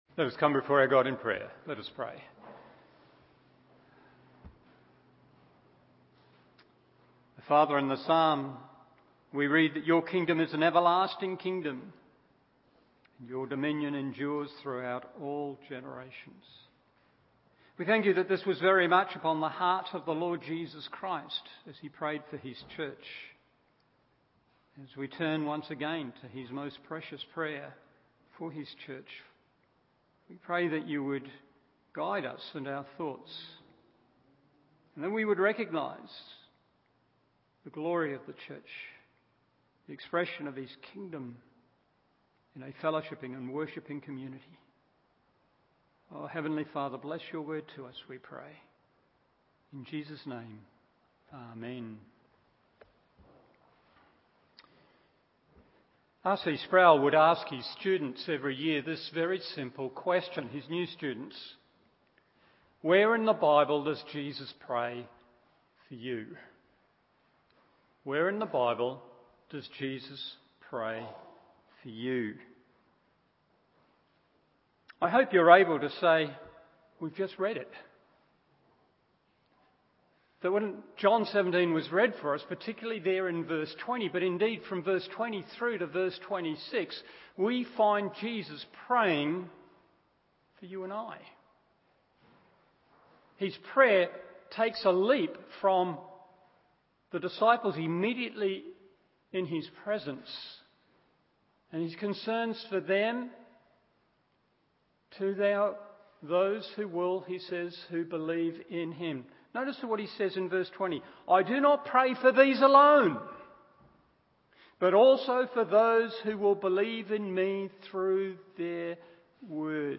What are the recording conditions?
Morning Service